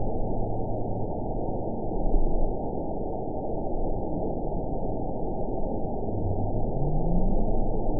event 921798 date 12/19/24 time 03:36:24 GMT (6 months ago) score 9.29 location TSS-AB04 detected by nrw target species NRW annotations +NRW Spectrogram: Frequency (kHz) vs. Time (s) audio not available .wav